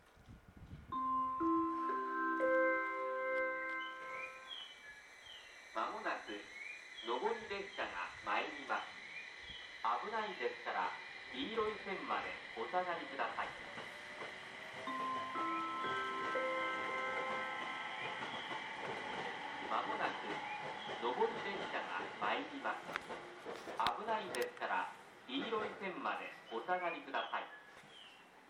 ３番線奥羽本線
接近放送普通　秋田行き接近放送です。